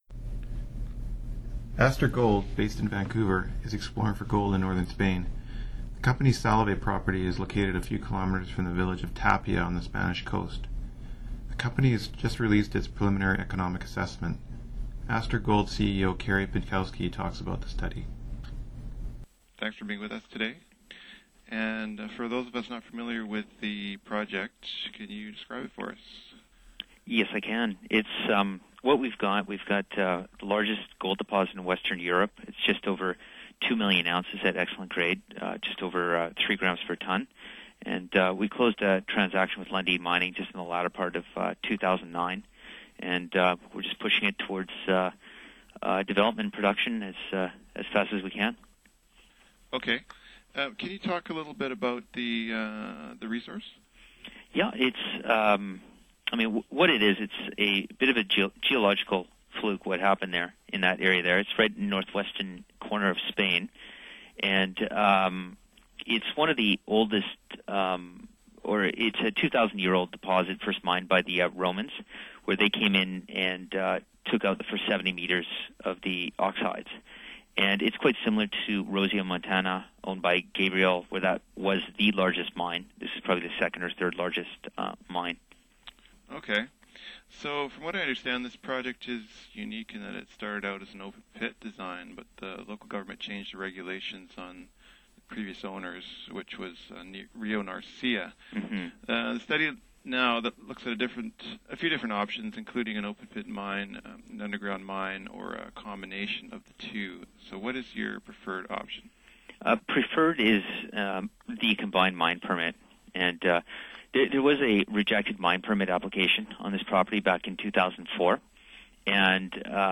astur gold_interview_edited
astur-gold_interview_edited.mp3